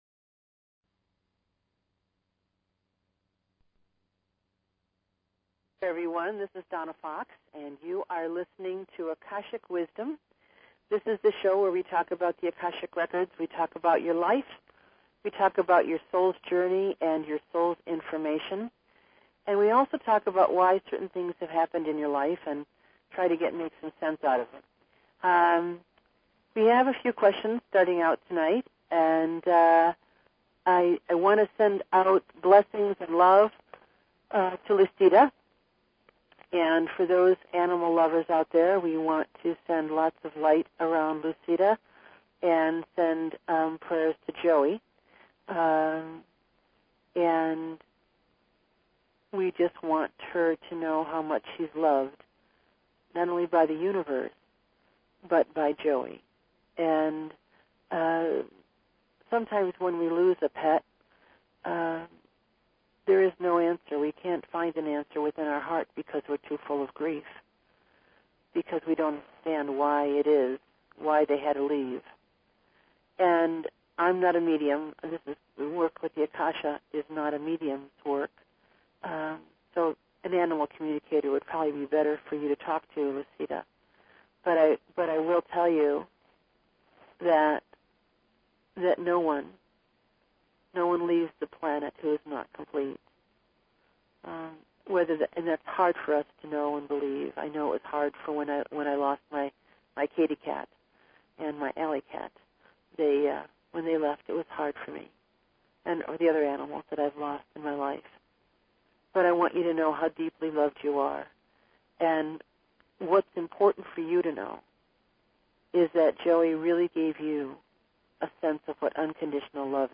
YOU are my guest! This show is dedicated to getting your questions about your life answered and receiving your "Message from the Akasha".